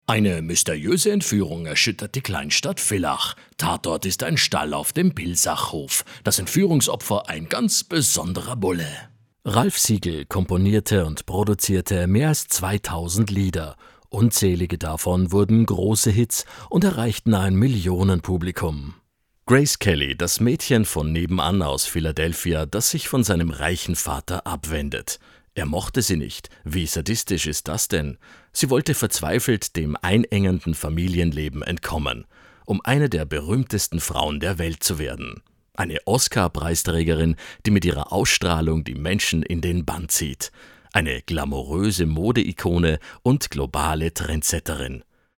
Sprecher für ORF TV-Magazine
Sprecher für Dokus und Reportagen: